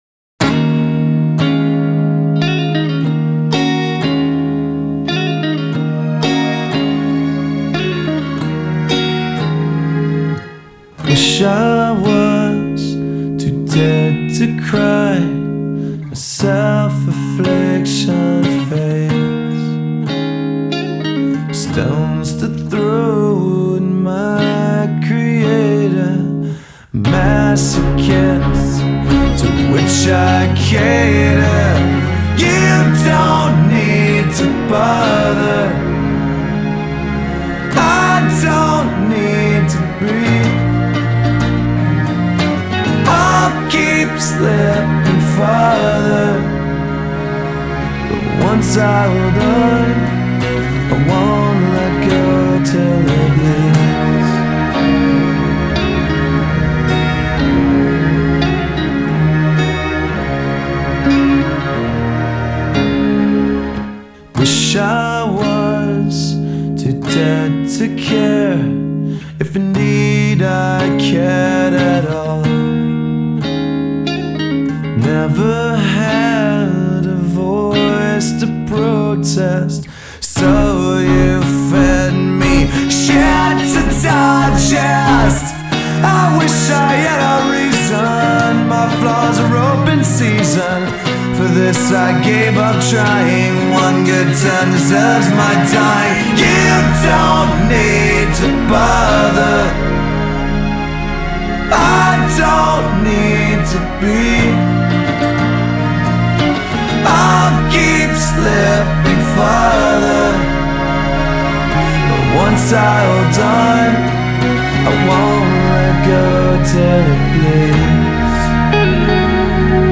alternative metal Hard Rock